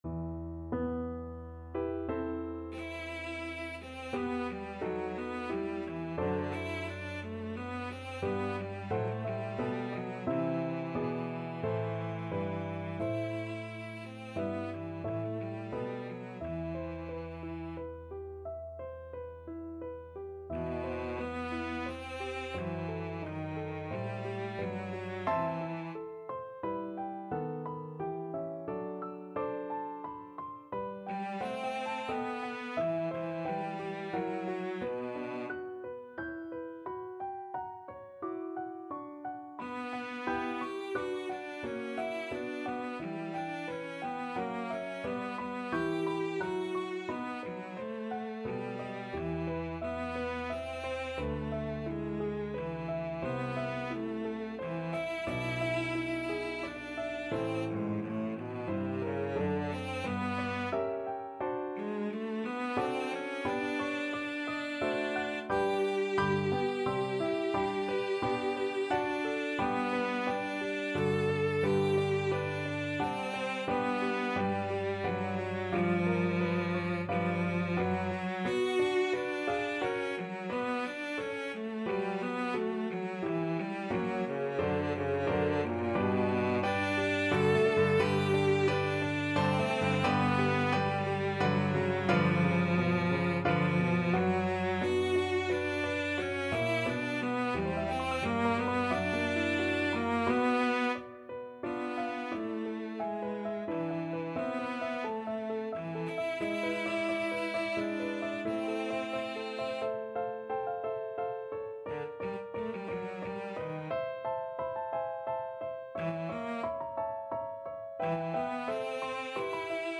Cello version
3/4 (View more 3/4 Music)
=88 Nicht schnell =100
Classical (View more Classical Cello Music)